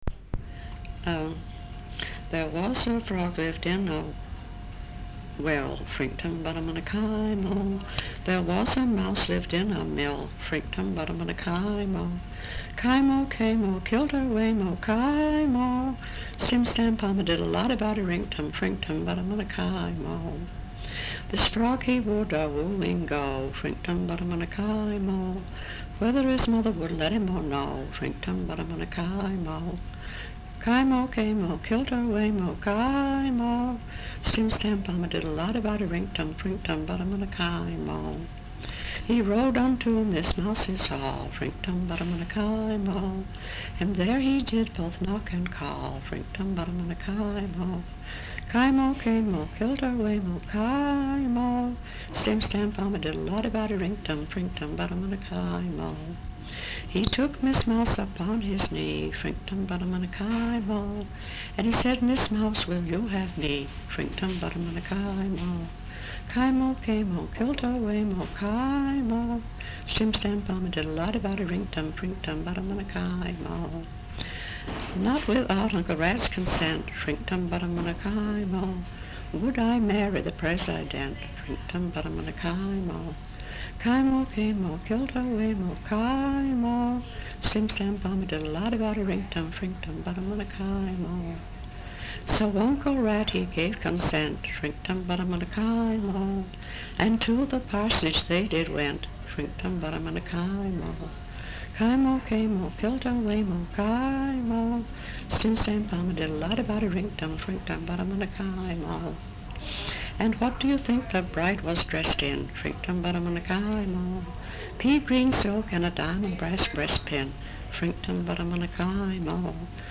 Folk songs, English--Vermont
sound tape reel (analog)
Brattleboro, Vermont